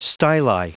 Prononciation du mot styli en anglais (fichier audio)
Prononciation du mot : styli